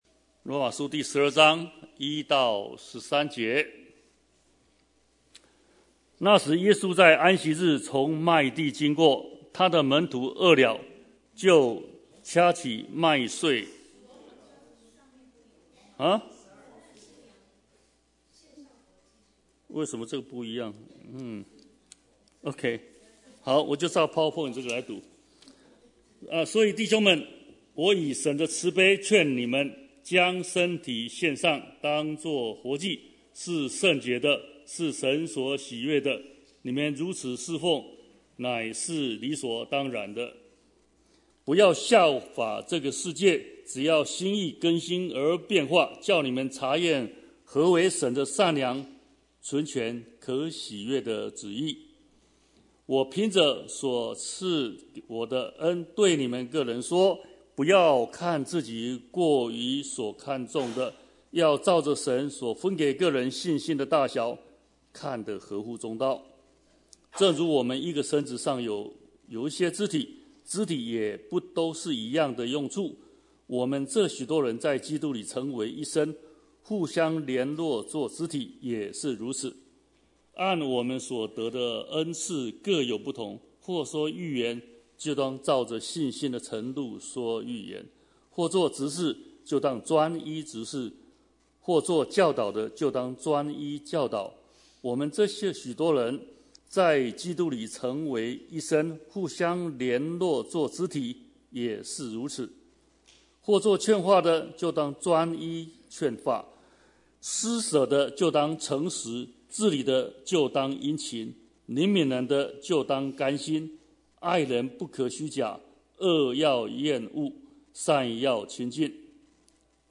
Bible Text: 羅馬書 12:1-13 | Preacher: